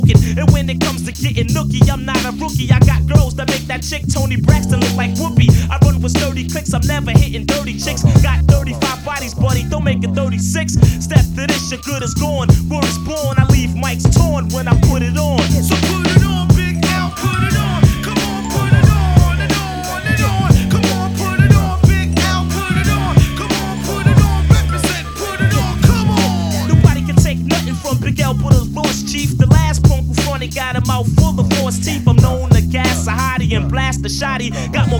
Жанр: Хип-Хоп / Рэп / Рок